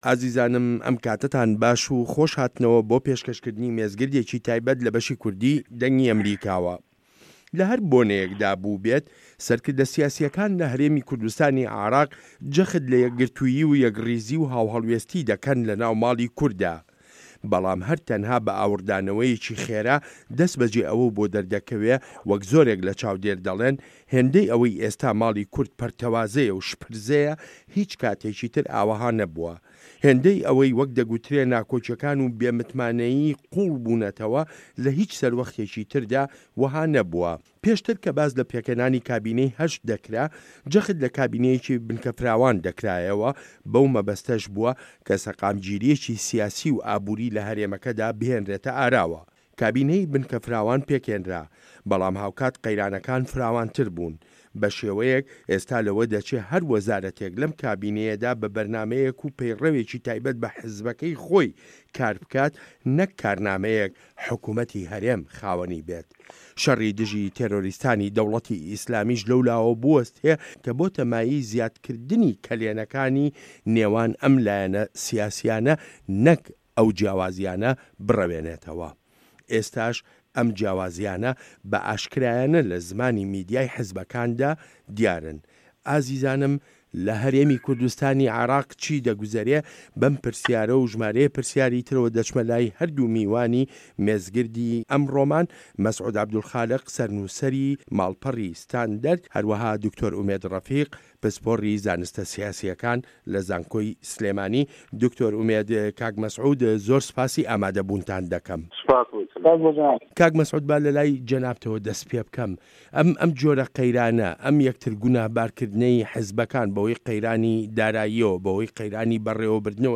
مێزگرد: هه‌رێمی کوردستان و قه‌یرانی دارایی و به‌ڕێوه‌بردن